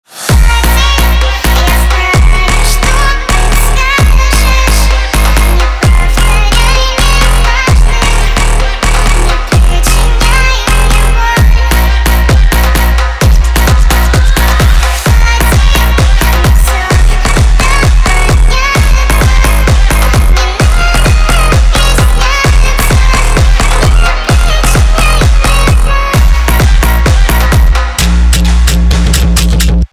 Engine Sound Effect